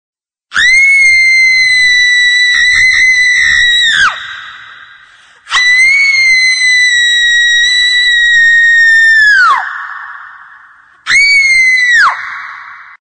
Звуки криков
Долгий крик юной девушки ааааа